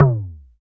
LOGTOM MD P.wav